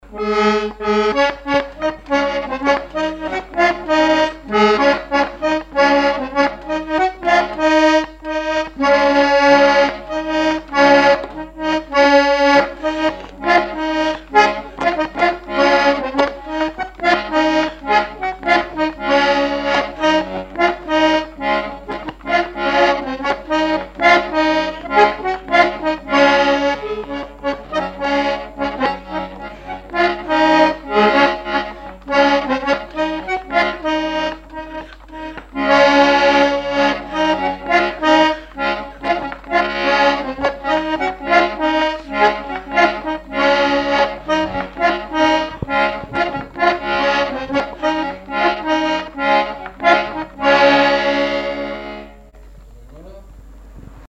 danse : ronde : grand'danse
Répertoire instrumental à l'accordéon diatonique
Pièce musicale inédite